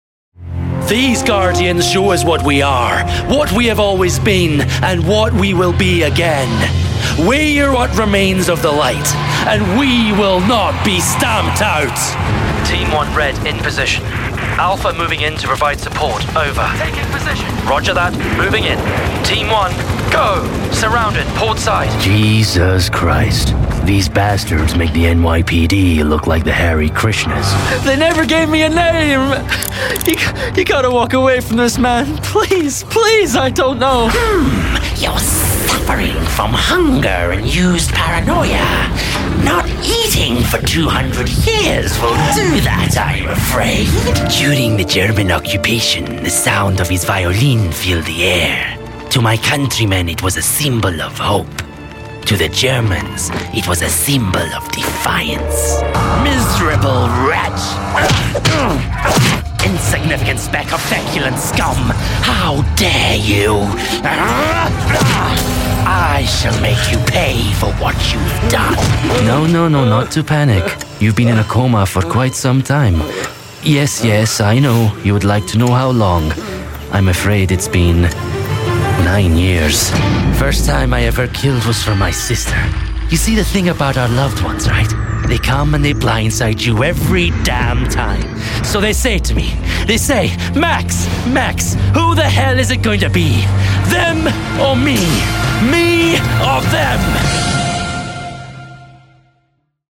Male
British English (Native)
Bright, Friendly, Natural, Reassuring
Scottish (natural), Glasgow (natural) Indian (near native)
Voice reels
Microphone: Sennheiser MKH416
I record from an acoustically treated Demvox recording booth.